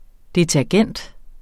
Udtale [ detæɐ̯ˈgεnˀd ]